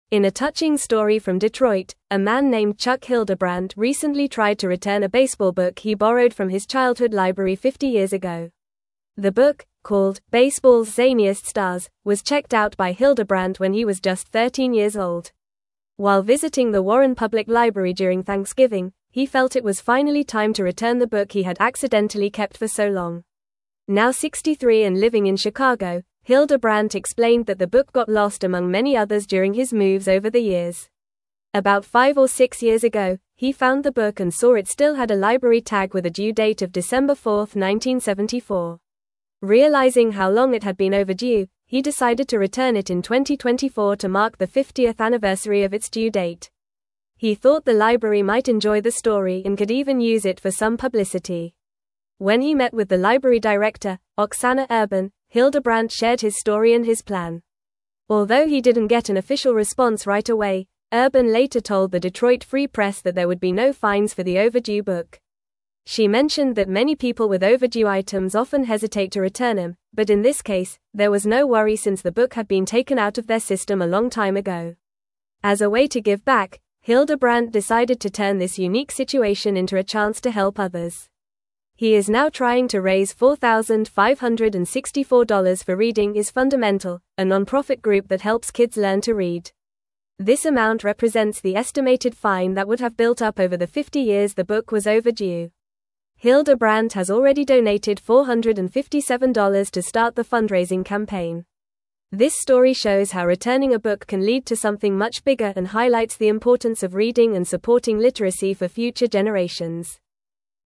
Fast
English-Newsroom-Upper-Intermediate-FAST-Reading-Man-Returns-Overdue-Library-Book-After-50-Years.mp3